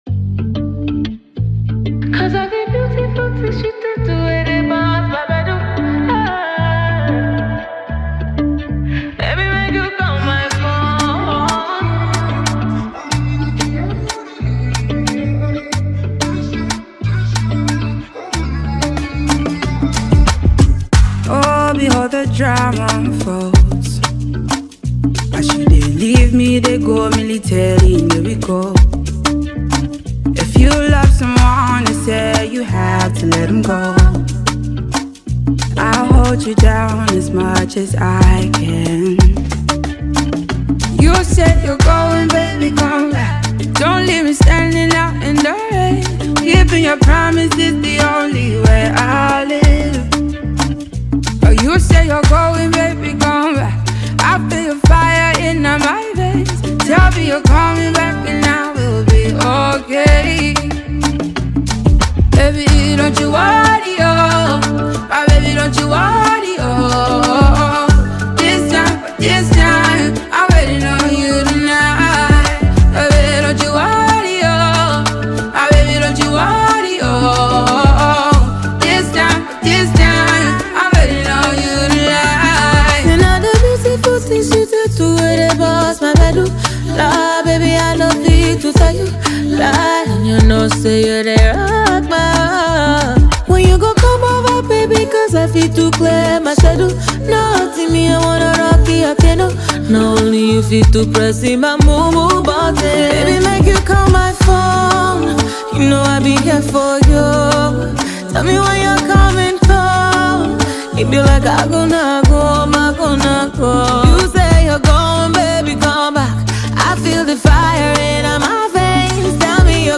The duo are well-known for their vocal prowess.
one big, sweet and soulful melody